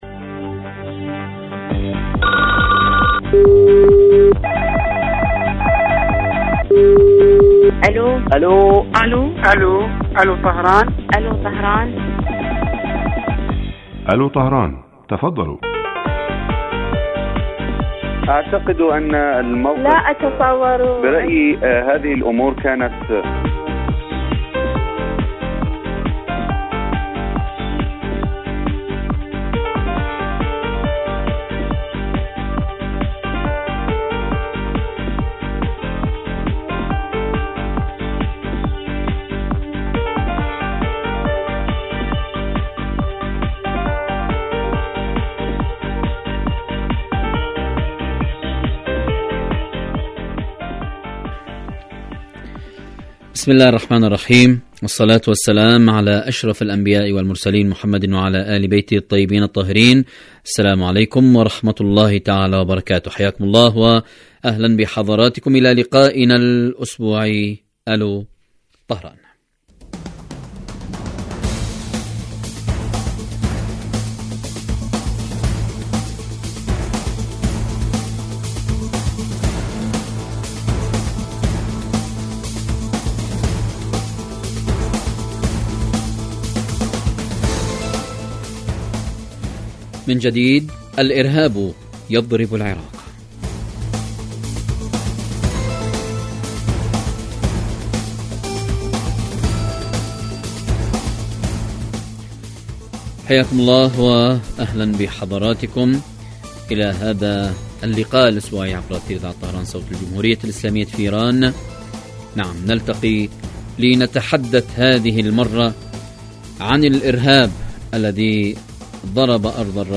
برنامج حي يهدف إلى تنمية الوعي السياسي من خلال طرح إحدى قضايا الساعة الإيرانية والعالمية حيث يتولى مقدم البرنامج دور خبير البرنامج أيضا ويستهل البرنامج بمقدمة يطرح من خلال محور الموضوع على المستمعين لمناقشته عبر مداخلاتهم الهاتفية.
يبث هذا البرنامج على الهواء مباشرة مساء أيام الجمعة وعلى مدى ثلاثين دقيقة